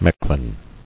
[Mech·lin]